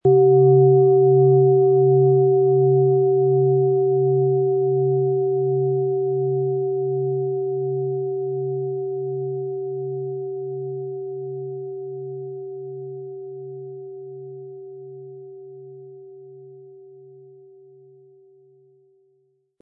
OM Ton
Handgearbeitete OM-Ton Klangschale.
Sie möchten den schönen Klang dieser Schale hören? Spielen Sie bitte den Originalklang im Sound-Player - Jetzt reinhören ab.
SchalenformBihar
MaterialBronze